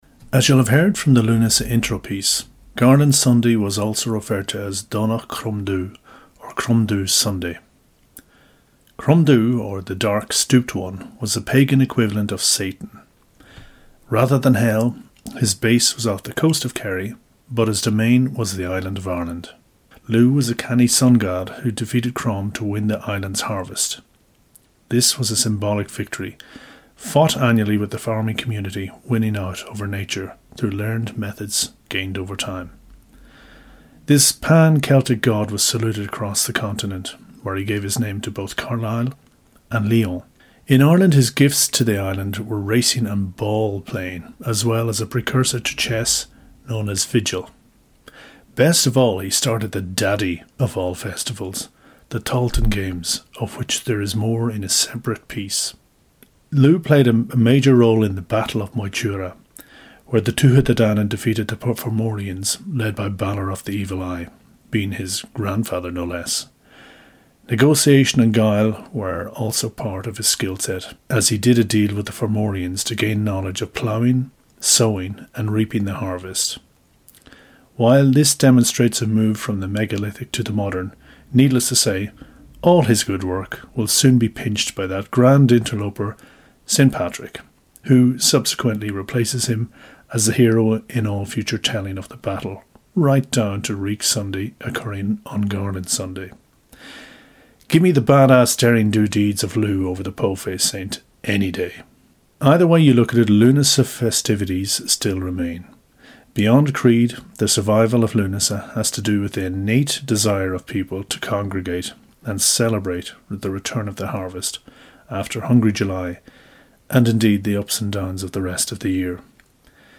An audio overview about the sun God Lugh who gave his name to the most vibrant of cross quarter days, Lughnasa in August. Lugh played a major role in the battle of Moytura where the Tuatha de Denann defeated the Fomorians led by Balor of the Evil Eye, being his grandfather no less.